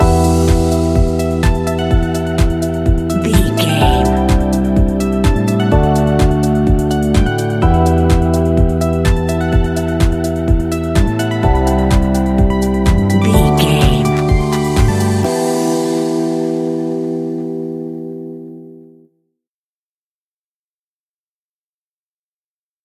Aeolian/Minor
uplifting
energetic
bouncy
funky
bass guitar
synthesiser
electric piano
drum machine
funky house
groovy
upbeat